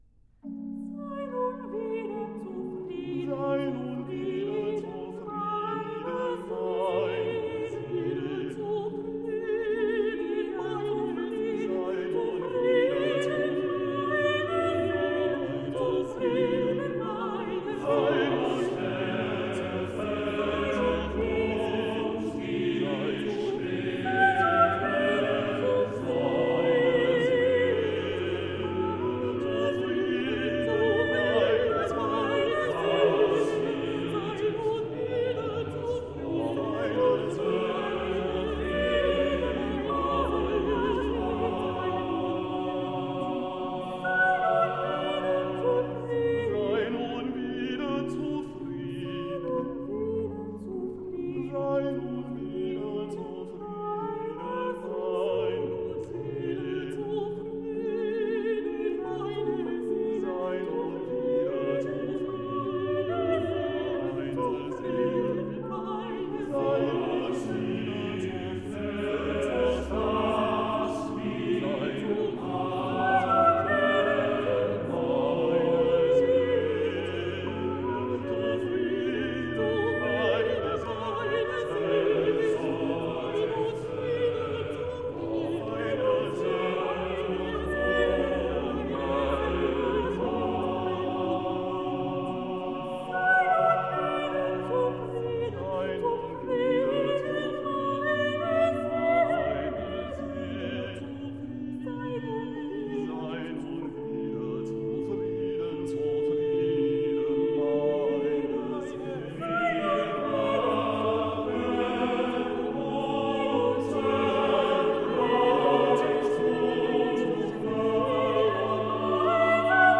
Sei nun wieder zufrieden (Chorus)